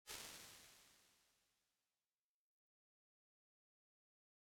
229-R2_CoolPlate.wav